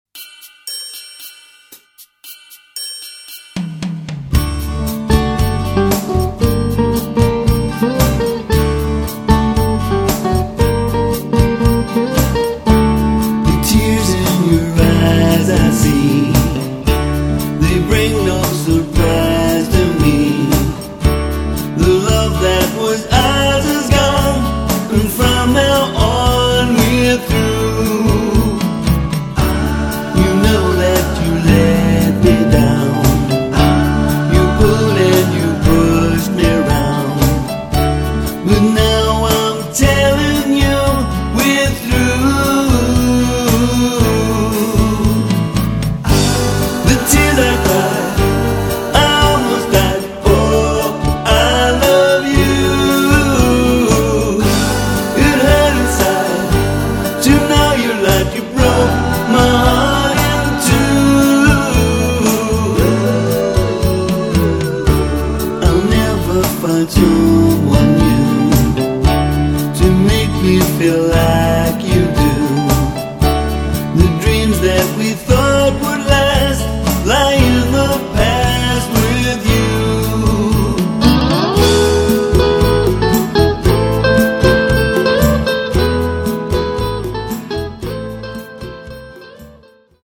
Venue: Own studio